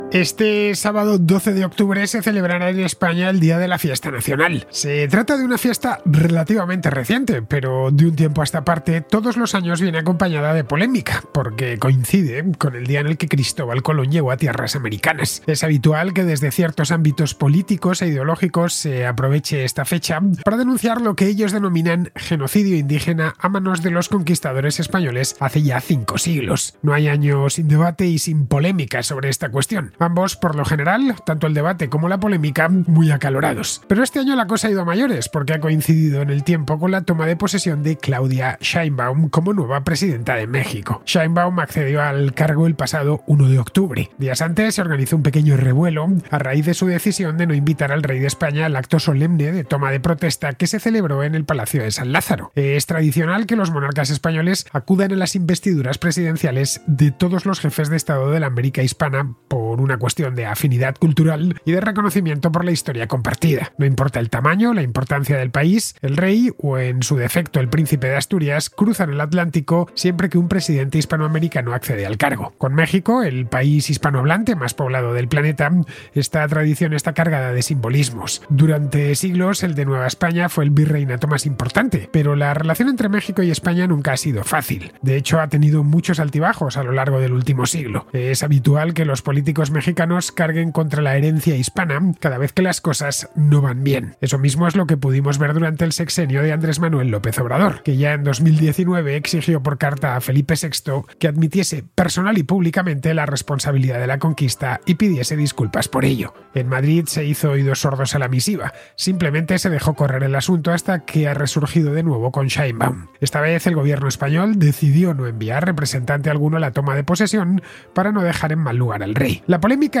Serie de Audios, fragmentos de programas de radio